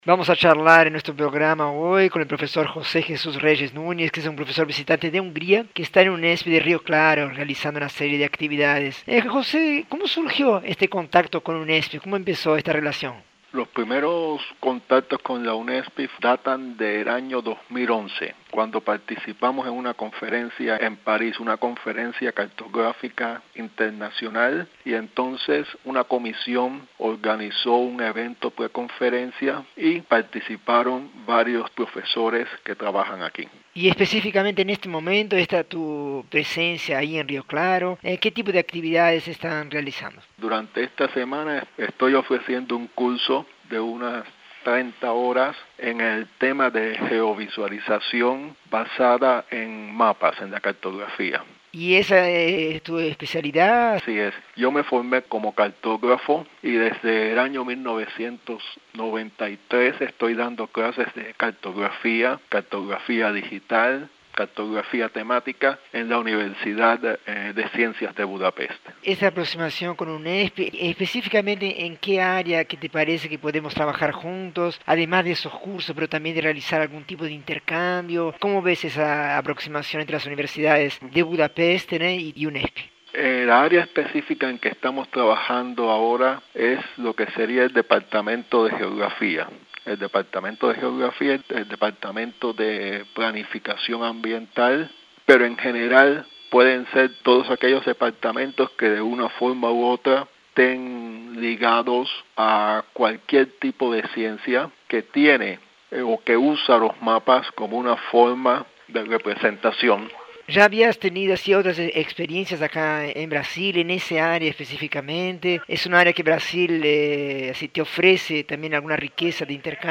Entrevista com pesquisador da Eötvös Loránd Tudományegyetem.
Reúne entrevistas com escritores e profissionais das mais diversas áreas que falam de suas criações e pesquisas.